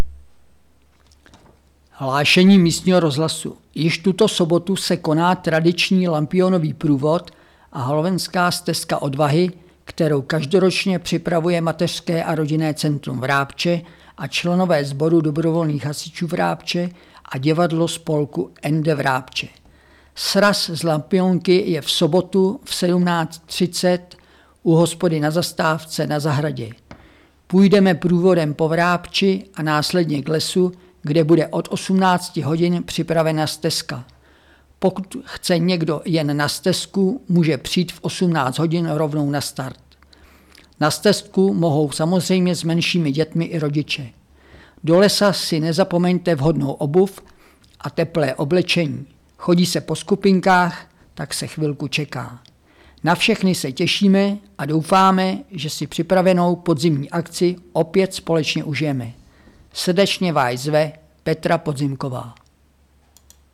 30.10.2024Hlášení rozhlasu